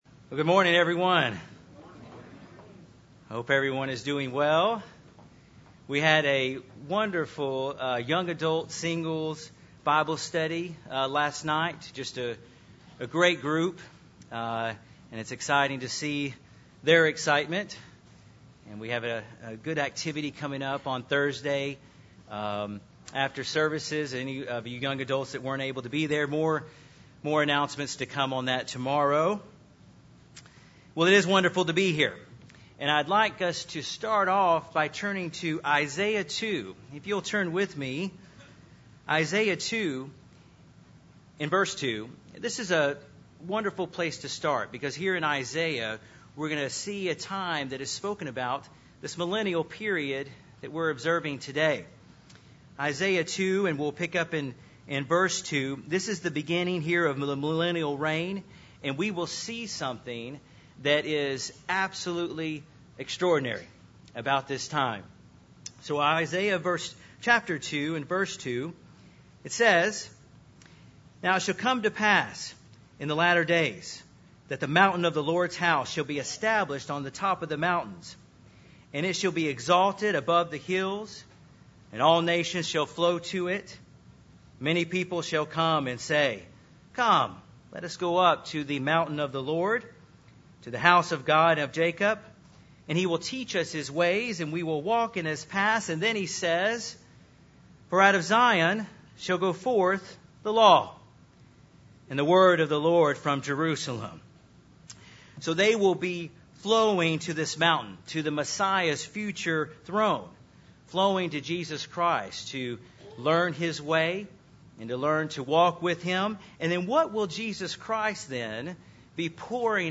This sermon was given at the Branson, Missouri 2015 Feast site.